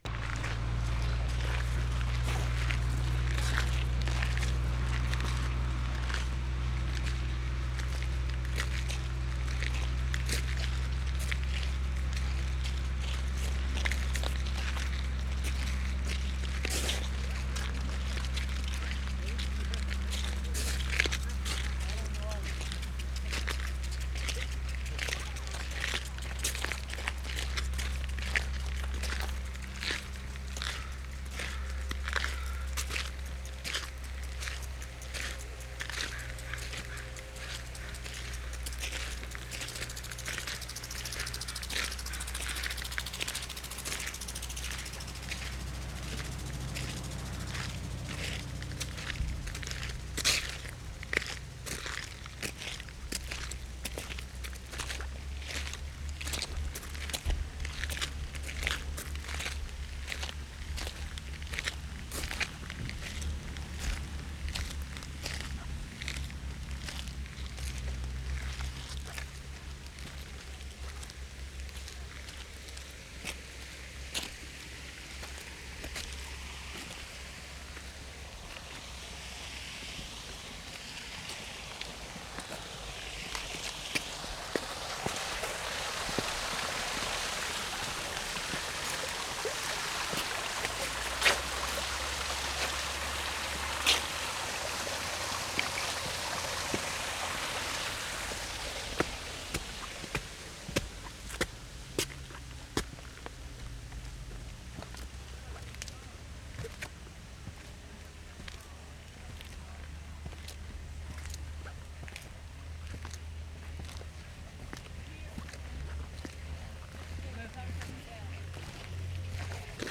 6. NITOBE (JAPANESE) GARDENS 3'20"
7. Past water sprinkler.
1'15" pause by running stream, then continue.
2'05" group of people (footsteps, voices) passes by. Recordist's footsteps continue. Good, on gravel.
2'25" pause, workman sweeping, humming pump in the background.